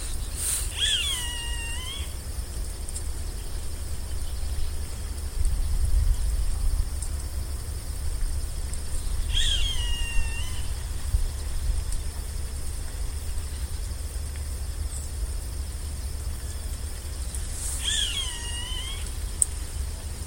Roadside Hawk (Rupornis magnirostris)
Life Stage: Adult
Location or protected area: Reserva Natural del Pilar
Condition: Wild
Certainty: Photographed, Recorded vocal